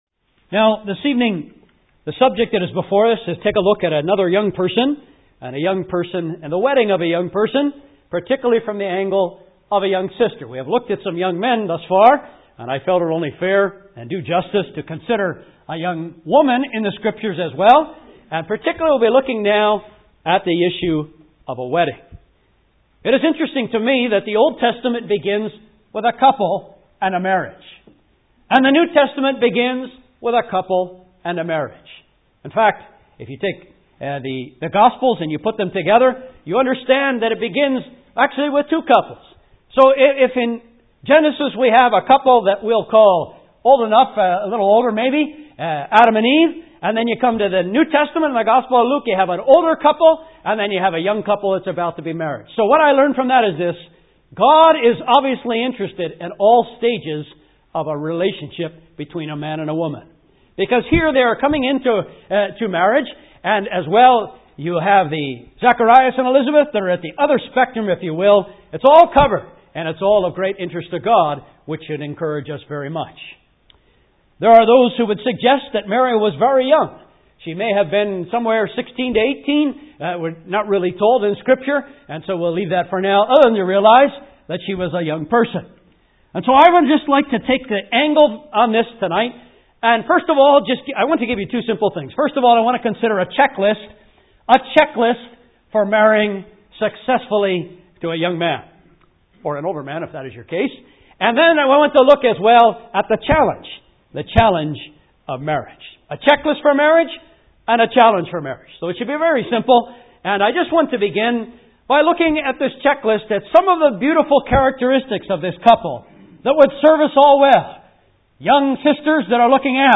preaches on some scriptural keys to a godly marriage based on the narrative of Mary and Joseph. What was Mary taking into account as she considered marrying Joseph? His maturity, masculinity, testimony, history, spirituality and responsibility.